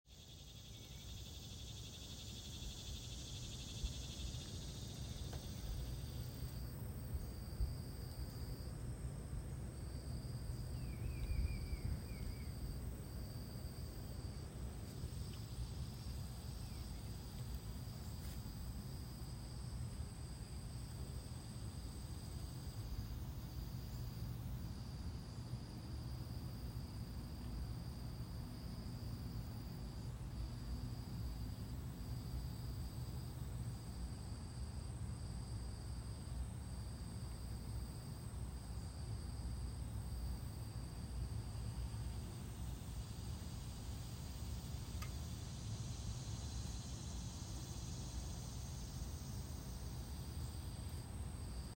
Recording of some cicada song swells in my back yard just now. Recording made with voice memo app on my phone, so my apologies for poor quality. The cicada swells are a wonderful summer sound for me. They make me feel nostalgic and dreamy.